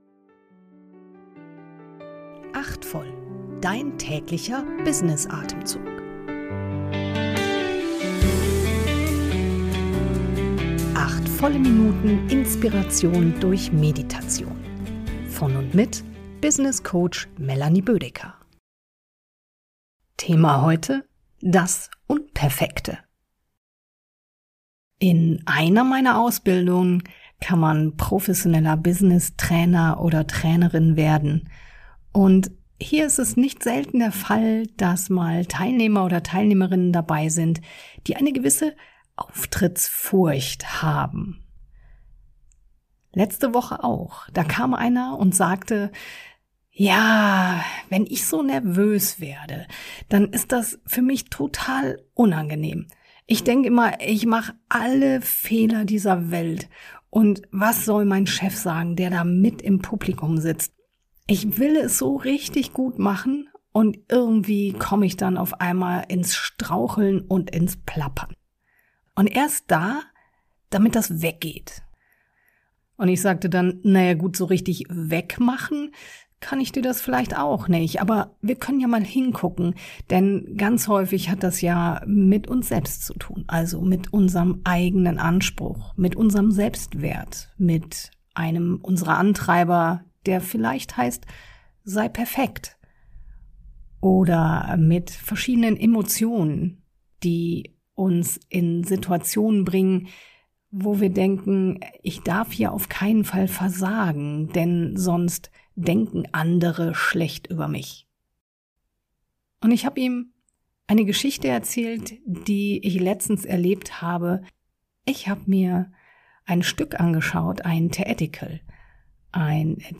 eine geleitete Kurz-Meditation.